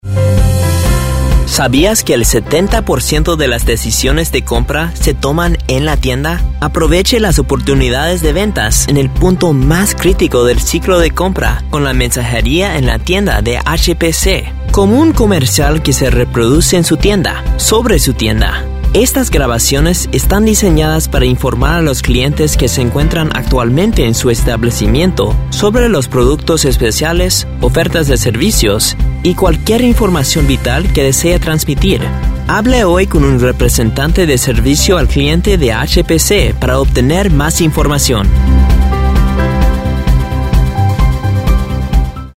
Professional Voice Talent Choices